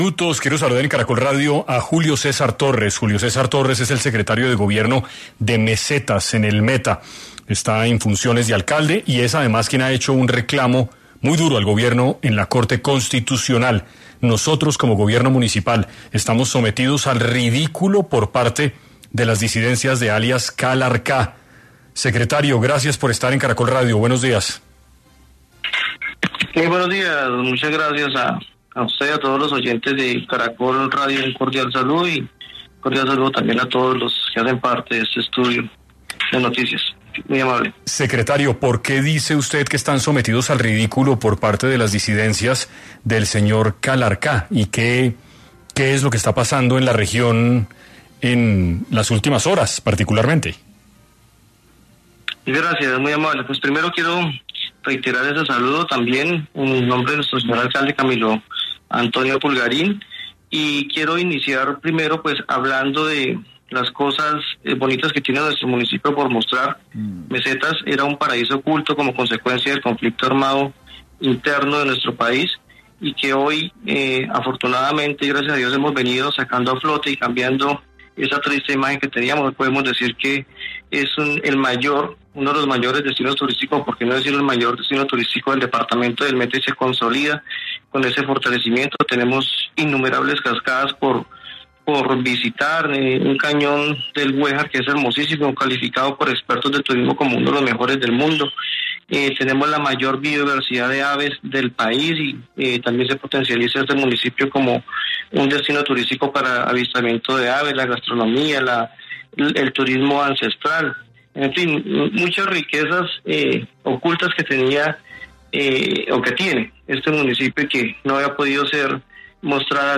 En entrevista con 6AM de Caracol Radio, Julio César Torres, secretario de Gobierno de Mesetas (Meta) y actual alcalde encargado, lanzó un contundente reclamo al Gobierno Nacional ante la situación de orden público que enfrenta su municipio debido a la presencia y accionar de las disidencias de alias Calarcá.